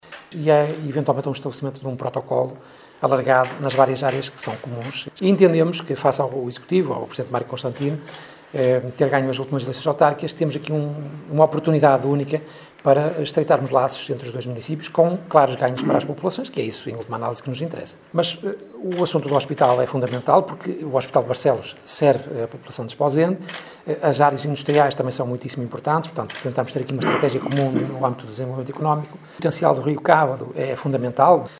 Benjamim Pereira, da Câmara de Esposende, fala na importância de um protocolo de colaboração para o desenvolvimento de várias áreas comuns aos dois municípios: